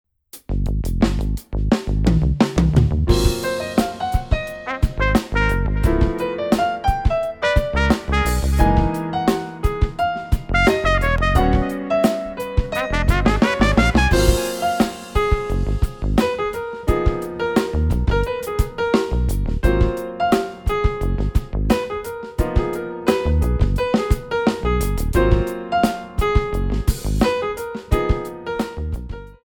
Pop-Jazz
8 bar intro
up-tempo beat